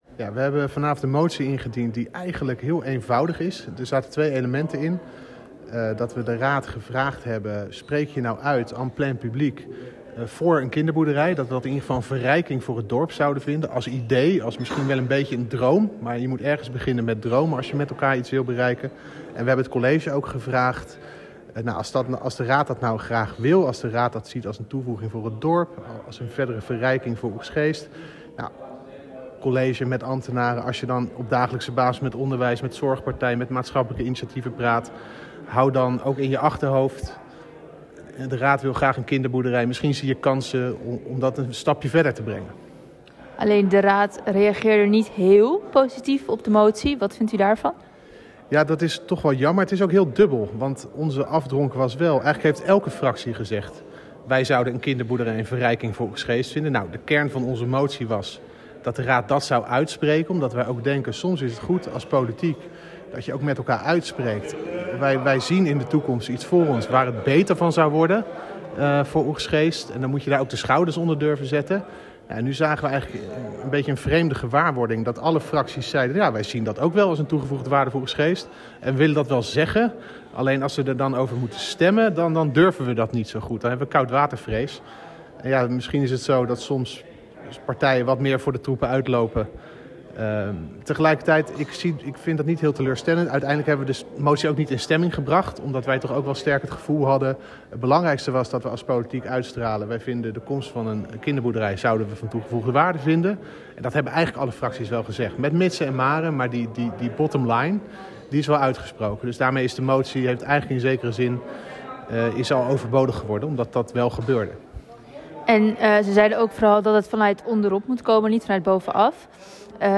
CDA-Raadslid Tobias van der Hoeven over het mogelijk maken van een kinderboerderij in Oegstgeest.